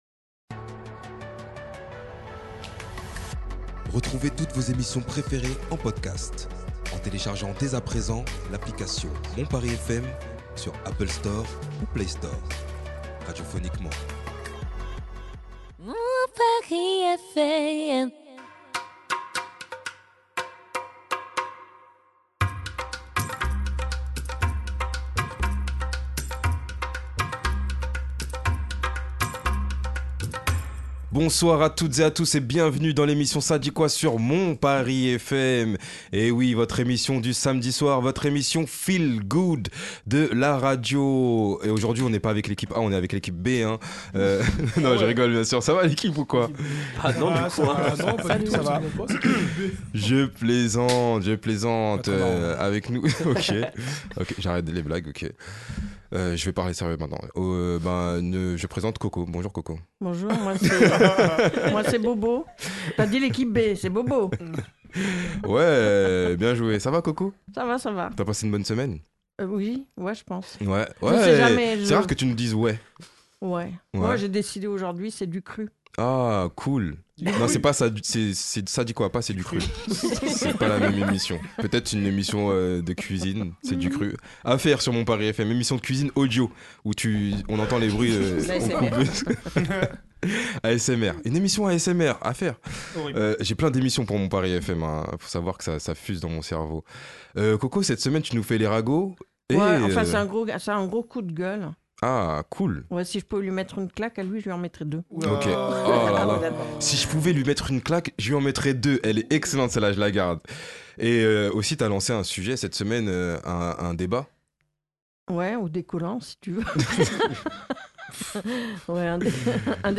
débat de la semaine